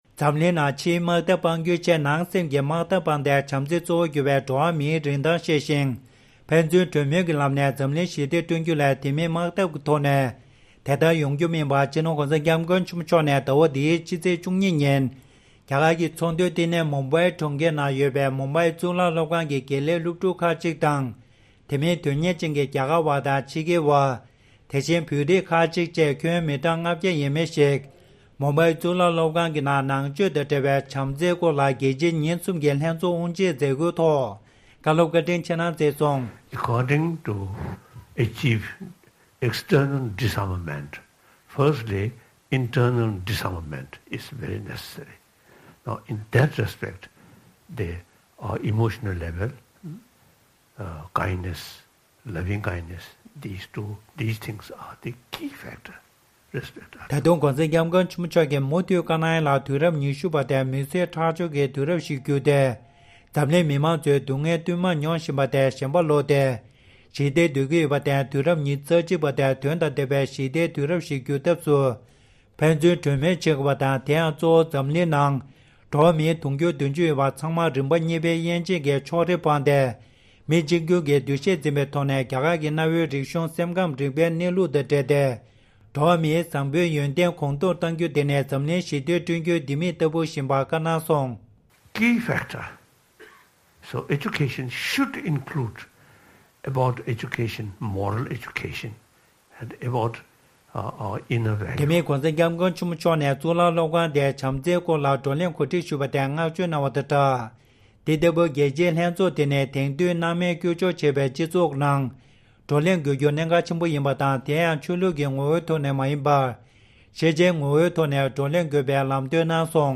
མུམ་བྷེ་གཙུག་ལག་སློབ་ཁང་གི་ནང་ནང་ཆོས་དང་འབྲེལ་བའི་བྱམས་བརྩེའི་སྐོར་ལ་ཉིན་གསུམ་རིང་གི་རྒྱལ་སྤྱིའི་ལྷན་ཚོགས་དབུ་འབྱེད་མཛད་སྒོའི་ཐོག